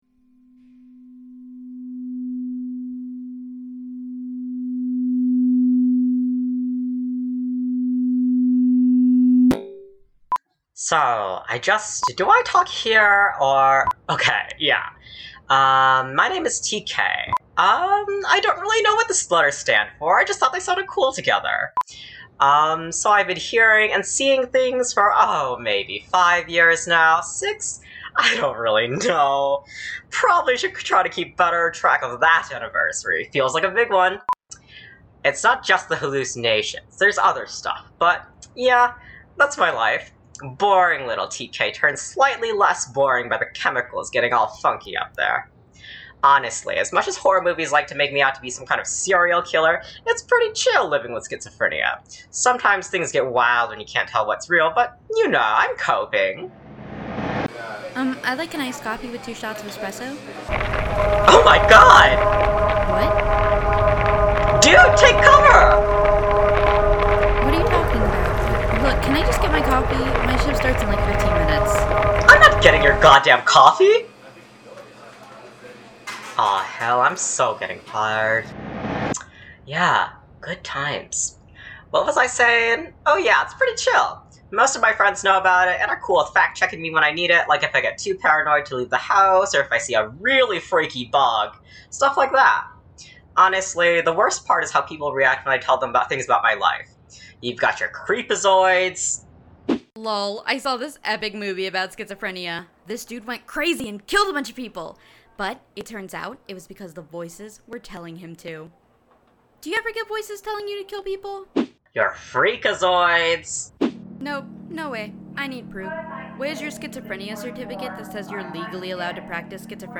TK VISION is an audio/zine exploration of schizophrenia through a whirlwind of different genres. It follows the happy-go-lucky TK as they navigate their symptoms for an ever-shifting audience.